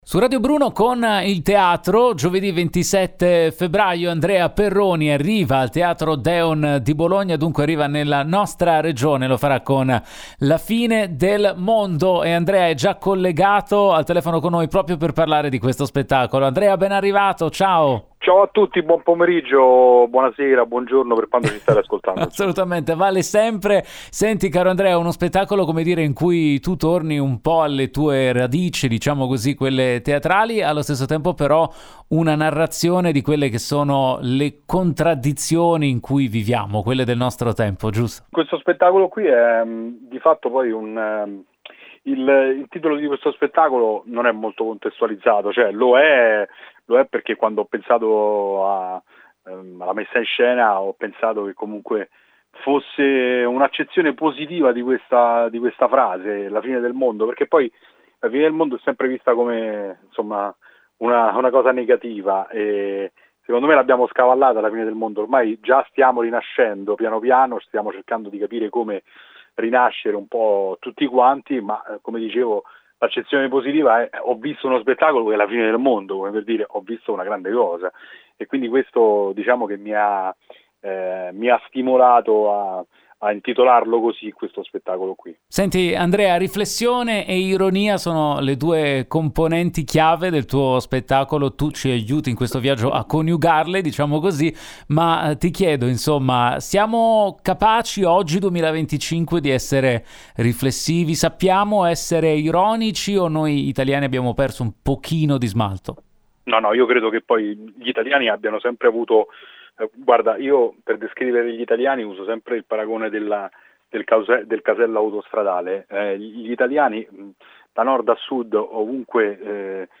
Home Magazine Interviste Andrea Perroni presenta “La Fine del Mondo”